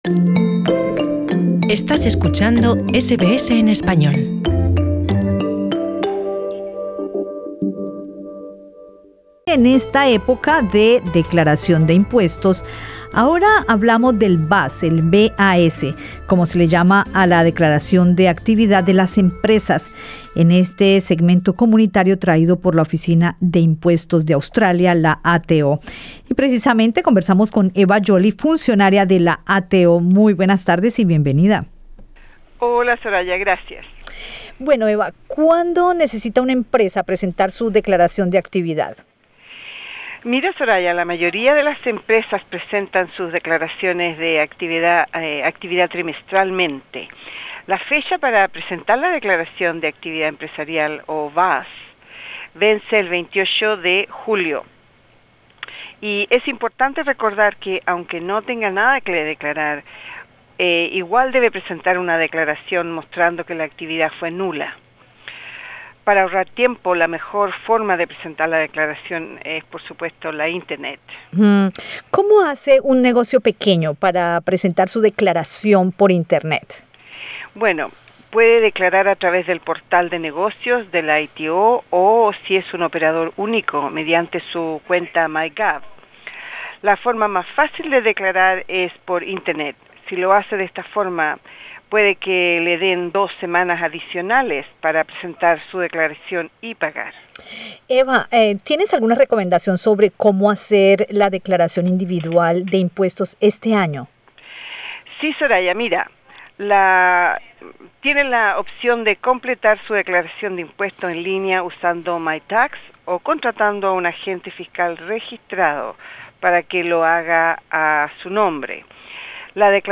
Esta información es proporcionada por la Oficina Australiana de Impuestos ATO. Entrevista con la funcionaria de ATO